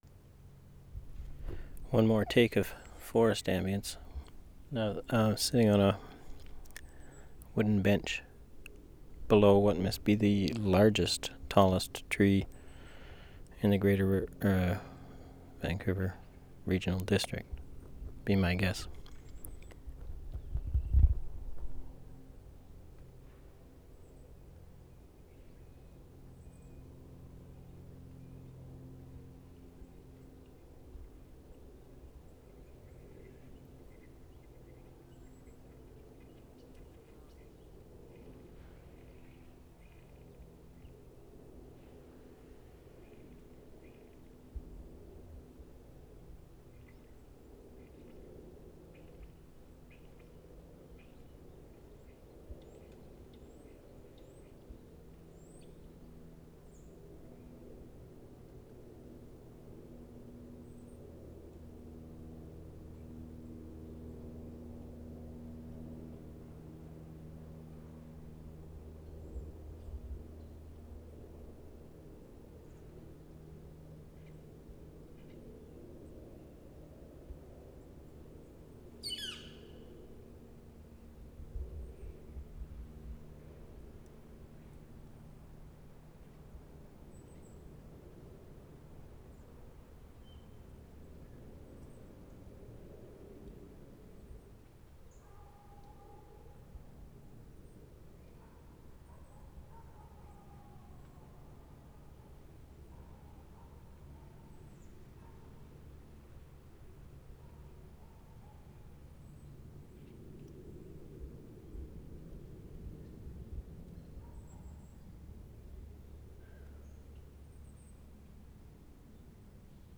WORLD SOUNDSCAPE PROJECT TAPE LIBRARY
Lighthouse Park, forest ambience 7:25
20. ID, distant plane, birds, eagles 1:19, distant dog at 1:40, interesting echoes of forest, insects, wind, distant traffic, quiet bird wings at 4:56, hikers at 5:20, footsteps into distance at 6:11, very quiet